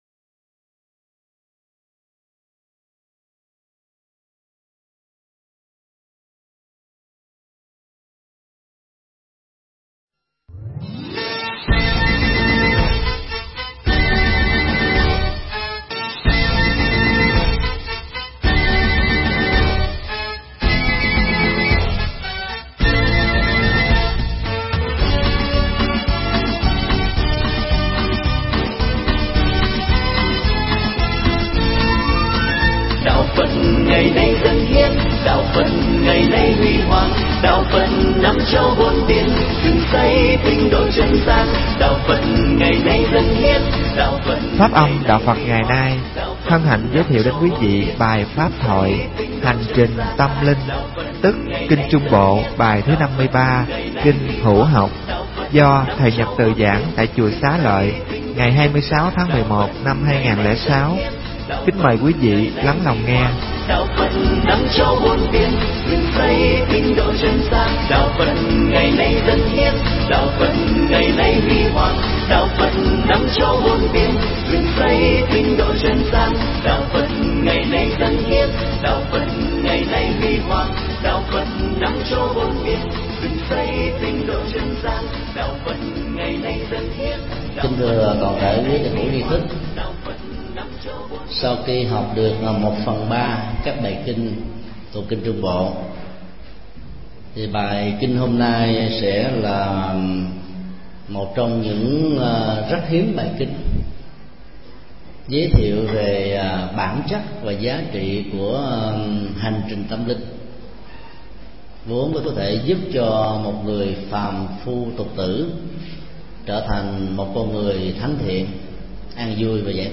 Mp3 Pháp thoại Kinh Trung Bộ 53
giảng Kinh Hữu Học 53 tại Chùa Xá Lợi